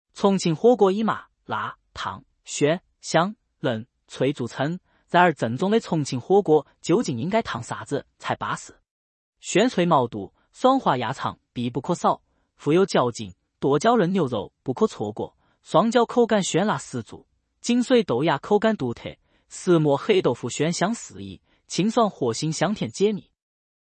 西南官话（四川）
西南官话（四川）.mp3